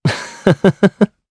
Esker-Vox_Happy2_jp_d.wav